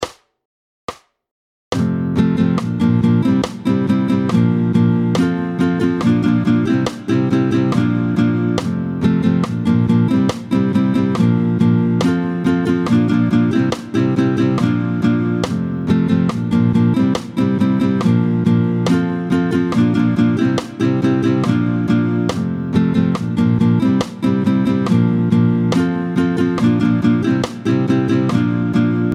Vite, en 2/2 tempo 140